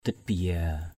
/d̪ɪt-bi-za:/ (d.) đất nước, vương quốc. nation, country, kingdom. nda ka brai ditbiya (DWM) Q% k% =b d{Tb{y% e rằng vương quốc sẽ tan rã.